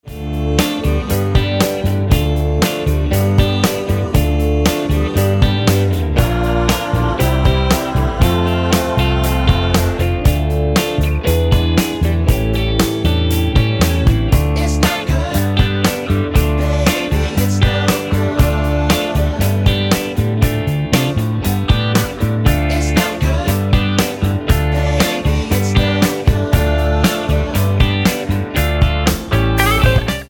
Tonart:Dm mit Chor